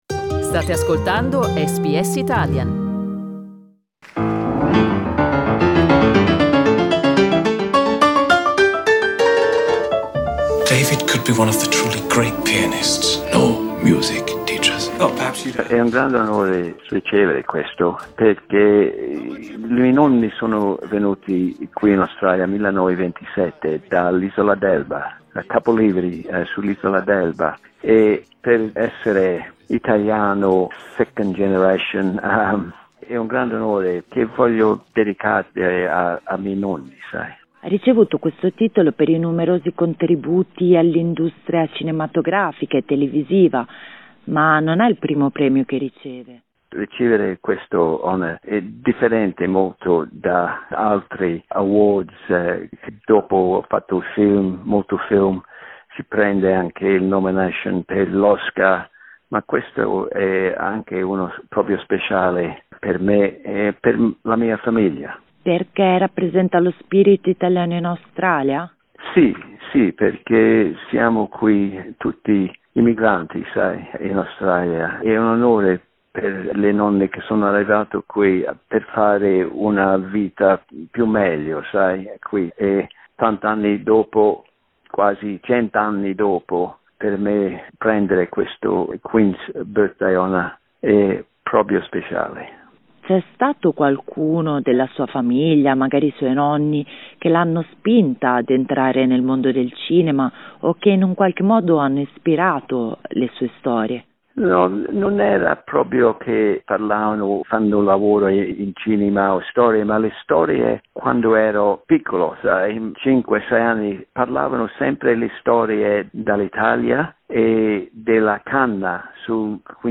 In this interview the Australian director and screenwriter talks about his Italian origins and his outstanding career.